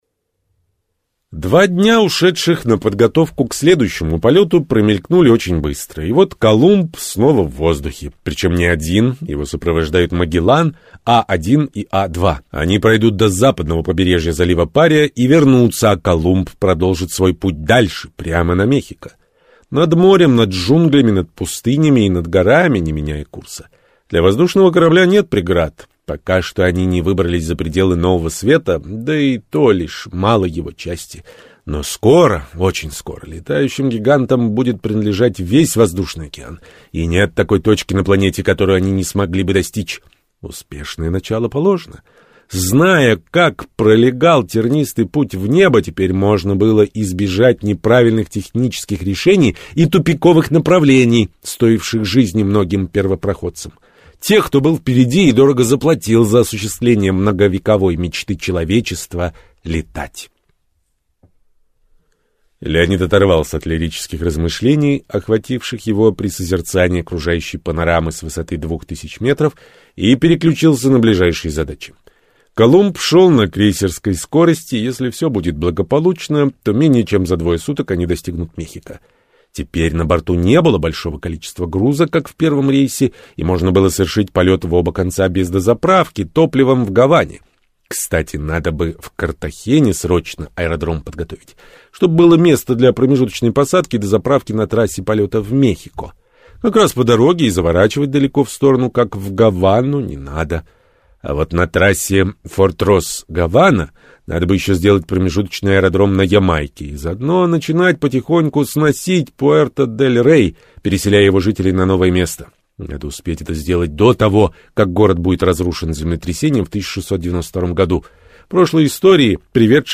Аудиокнига В начале пути | Библиотека аудиокниг
Прослушать и бесплатно скачать фрагмент аудиокниги